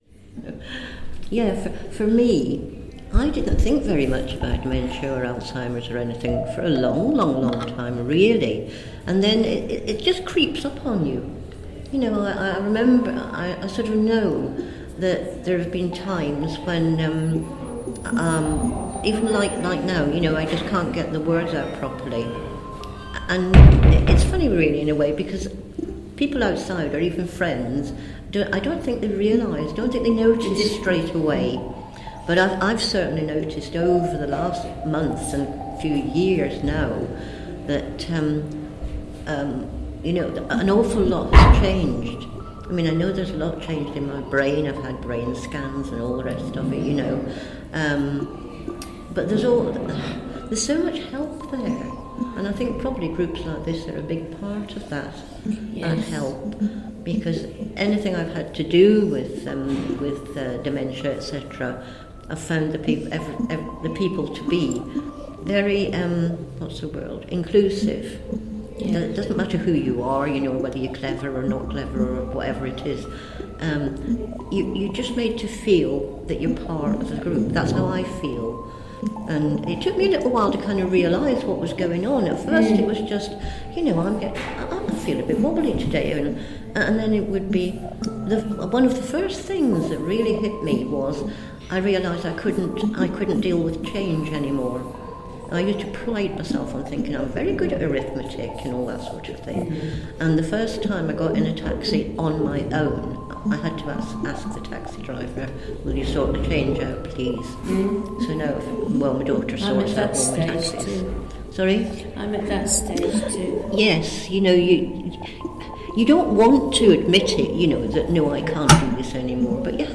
Theatre of Wandering Creative Soundscapes #6
Theatre of Wandering is a Coventry-wide engagement project, performance and self-led audio trail that draws upon a community’s experiences and reflections about what it is to live with dementia.